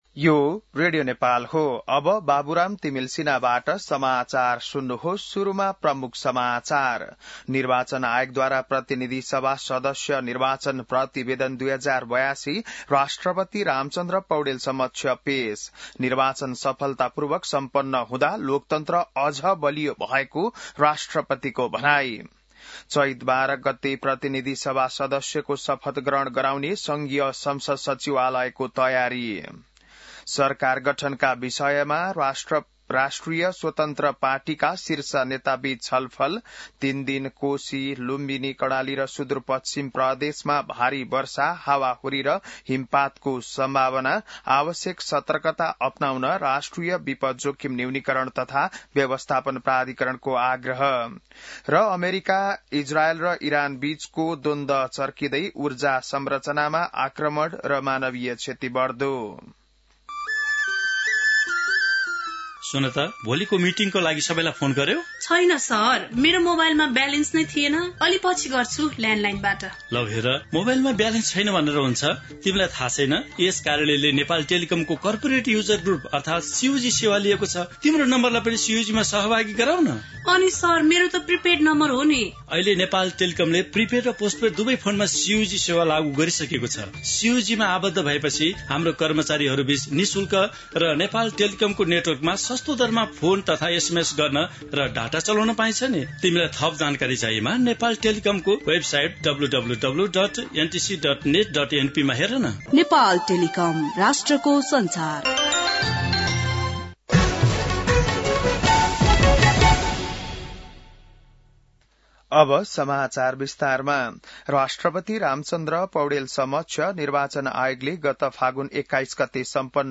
बिहान ७ बजेको नेपाली समाचार : ६ चैत , २०८२